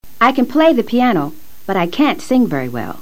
Escucha atentamente la pronunciación de CAN y CAN'T. Repítelo luego simultáneamente.
Debido a que la t final de CAN'T no se pronuncia con fuerza, es posible que a veces creas estar escuchando CAN.